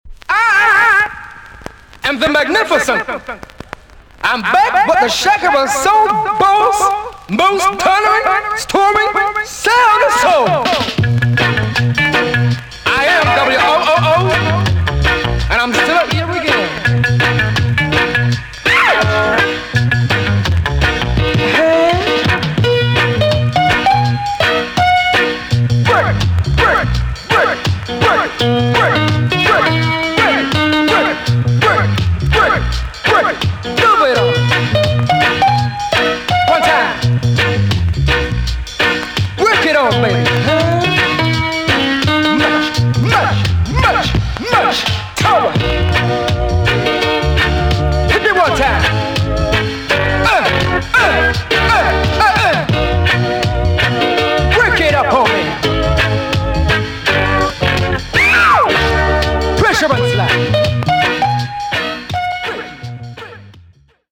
TOP >REGGAE & ROOTS
EX-~VG+ 少し軽いチリノイズがありますが良好です。